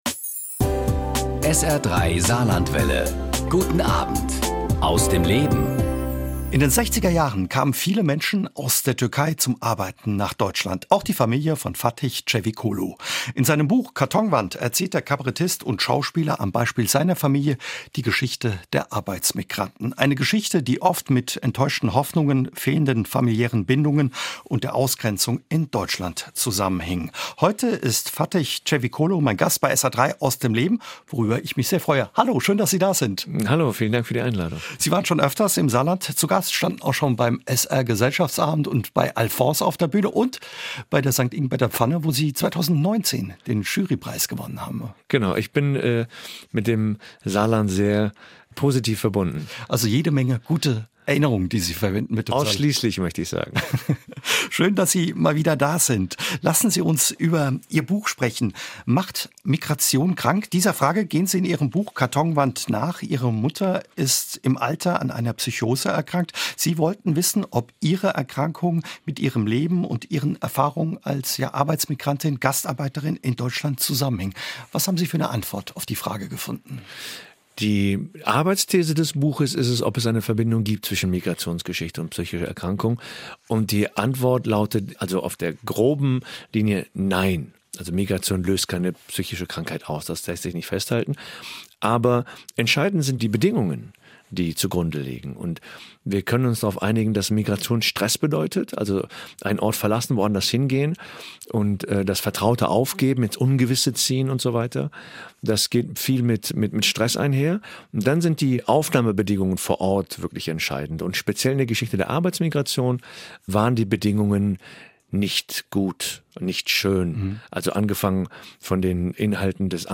Das Besondere dabei ist, dass er Zeit für das Gespräch mit seinen Gästen hat und in die Tiefe gehen kann.